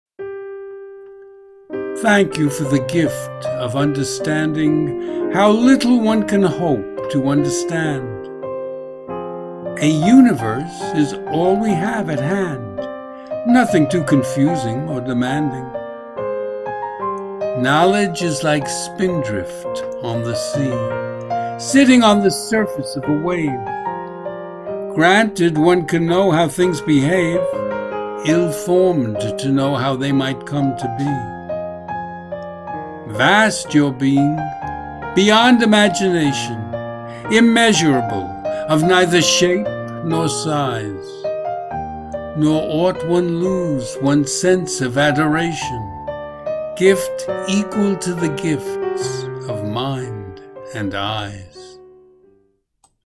Hear me read the poem as an MP3 file.